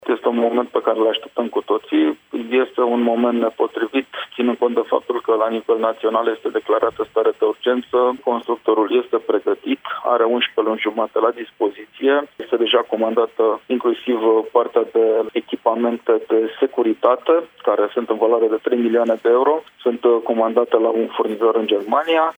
Anunțul a fost făcut din autoizolare, presedintele Consiliului, Adrian Vestea, intr-o interventie in direct la Radio Romania Brasov FM: